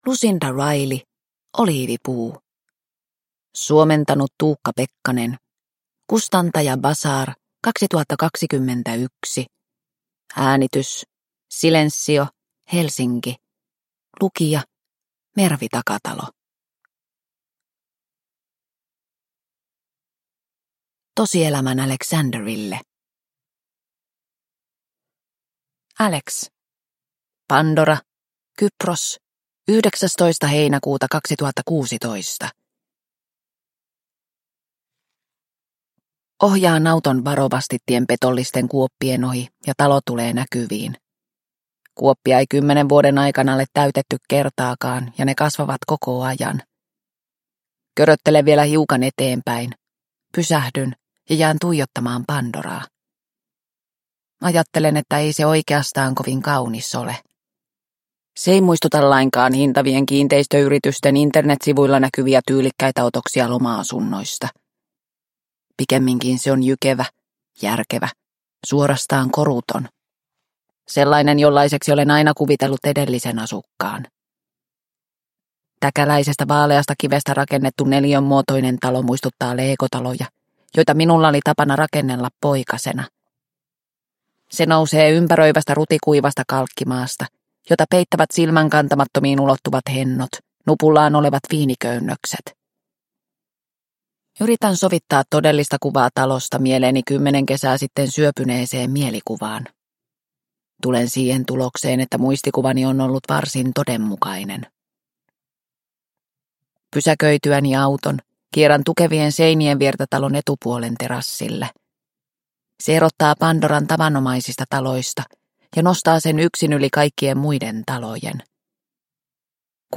Oliivipuu – Ljudbok – Laddas ner